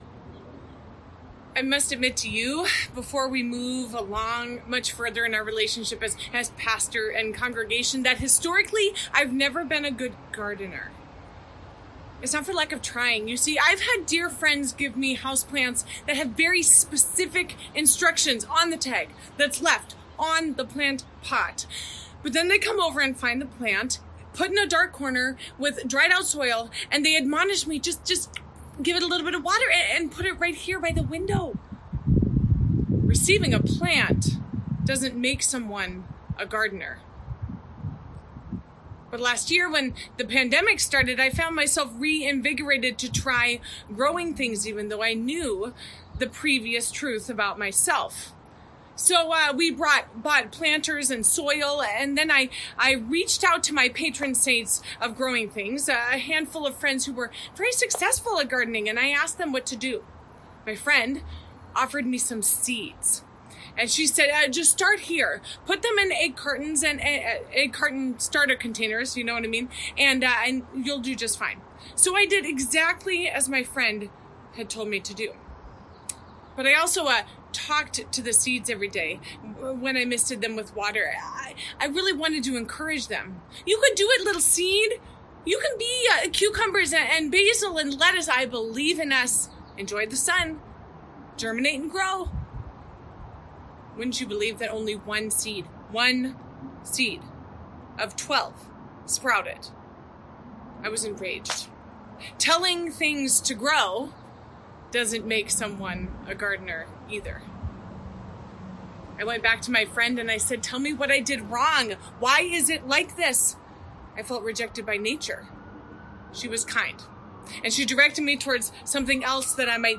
Message: “Awake Or Asleep